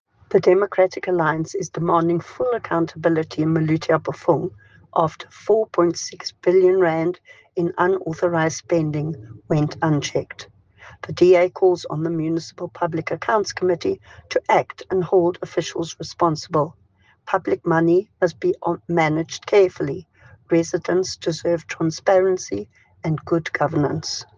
English soundbite by Cllr Alison Oates, Afrikaans soundbite by Cllr Eleanor Quinta and